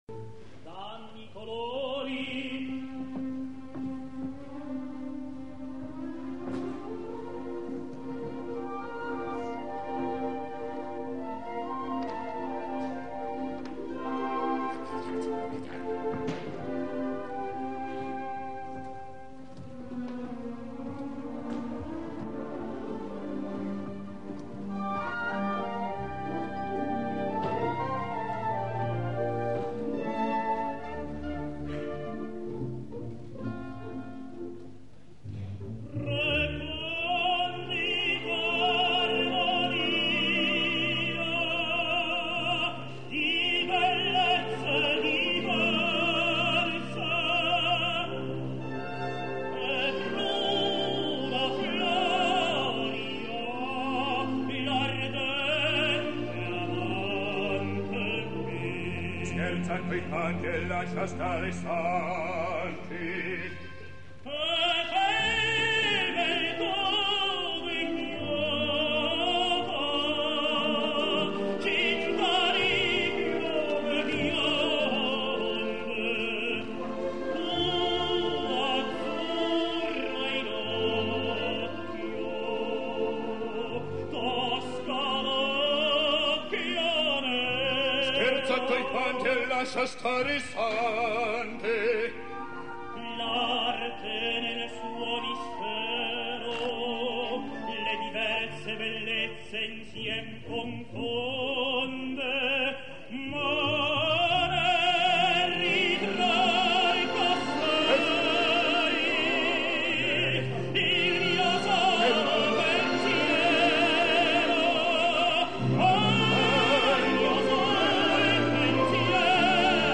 »melodramma / Schauerdrama«
Mario Cavaradossi [Tenor]
Il sagrestano [Bariton]